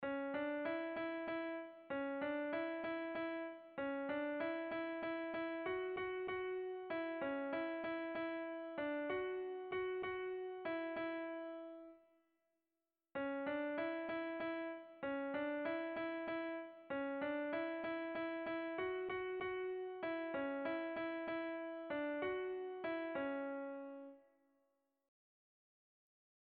Irrizkoa
Lauko handia (hg) / Bi puntuko handia (ip)
A1A2